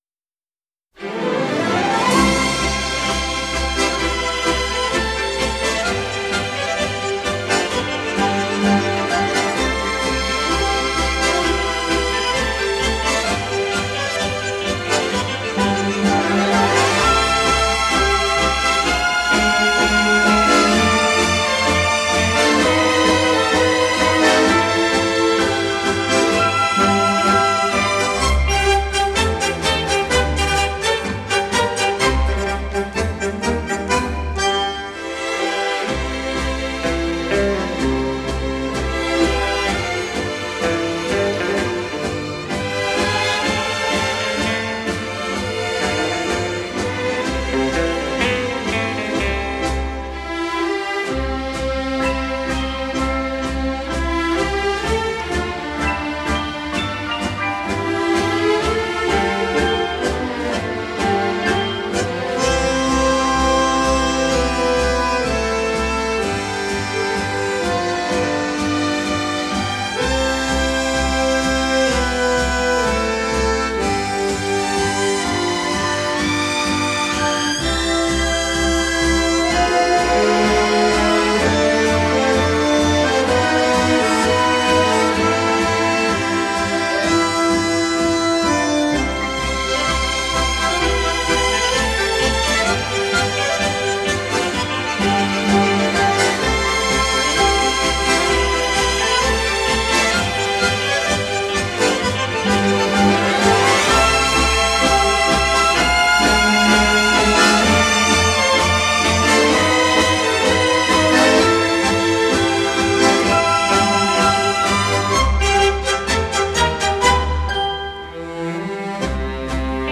1936年にタンゴの形にアレンジされた